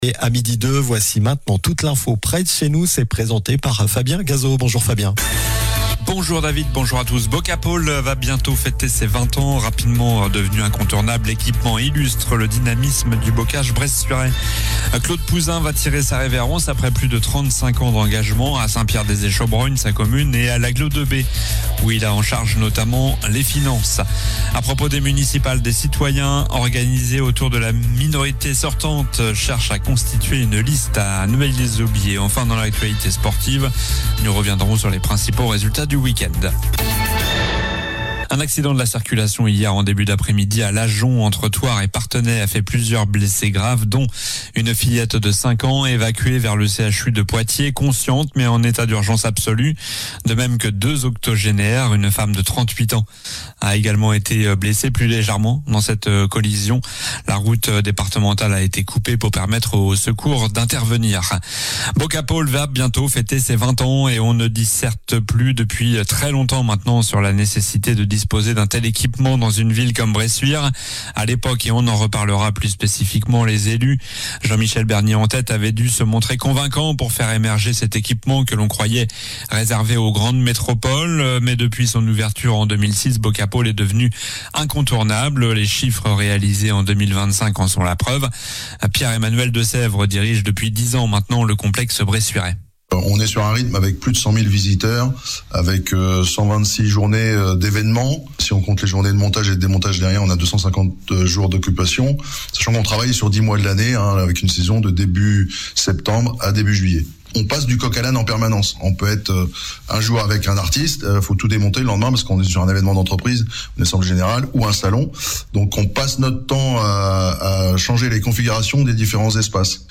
Journal du lundi 26 janvier (midi)